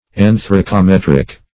Anthracometric \An`thra*co*met"ric\, a. Of or pertaining to an anthracometer.